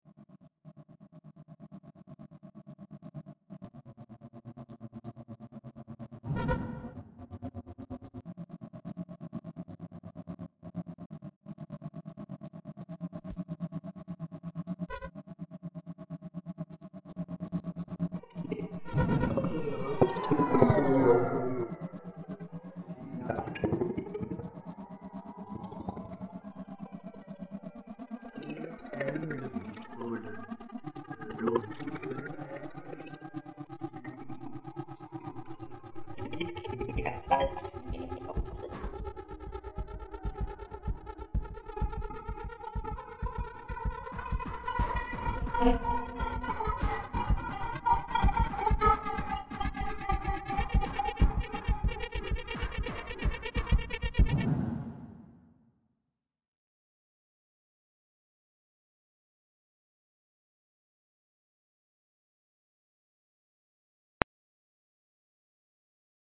pour saxophones, voix et bandes acousmatiques